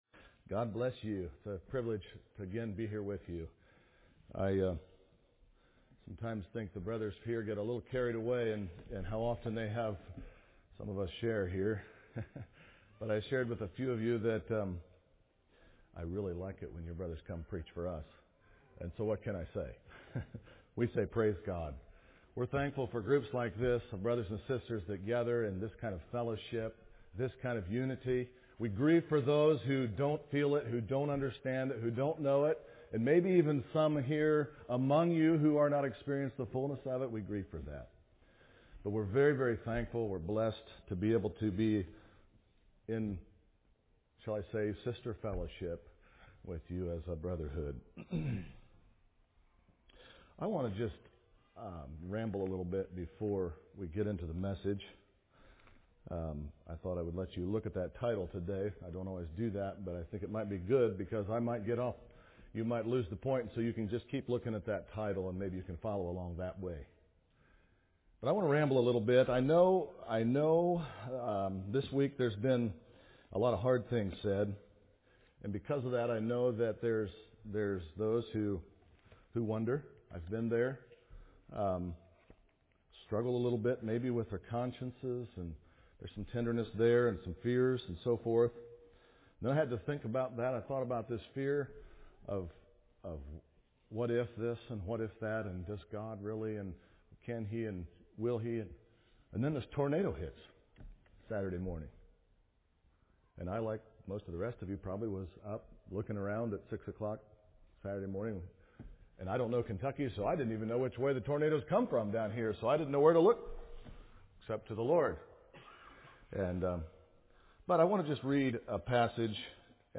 A message from the series "2021 - Messages."